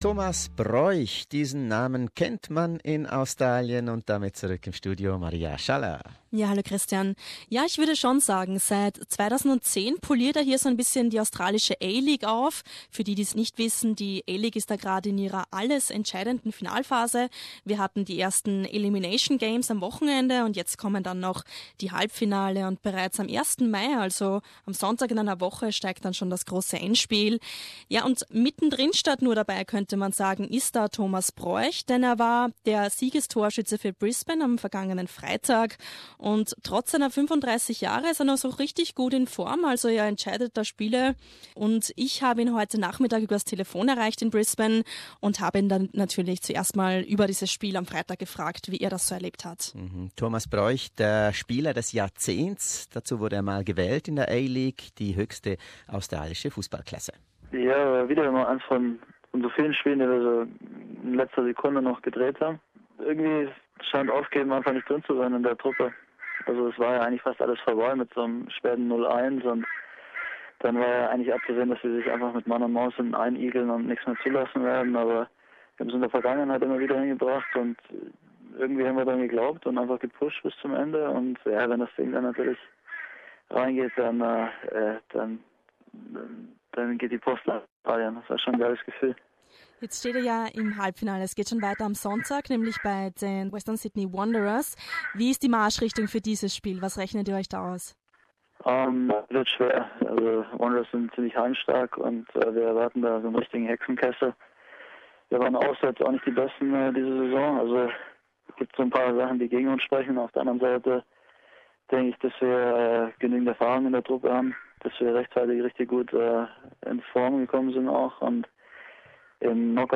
Deutschlands Fußball-Export Thomas Broich avancierte in einem dramatischen Elminierungs-Spiel zum Siegestorschützen seiner Brisbane Roar in der A-League. Mit mittlerweile 35 Jahren denkt der "Fußballer des Jahrzehnts" im Interview über ein Karrierende und eine mögliche Rückkehr nach Deutschland nach.